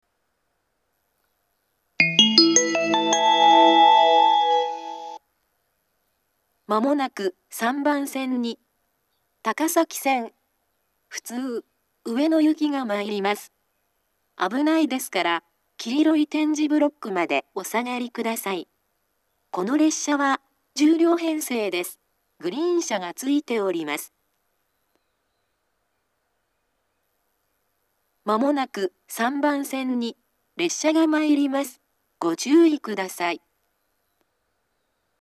２０１２年頃には放送装置が更新され、自動放送鳴動中にノイズが被るようになっています。
３番線接近放送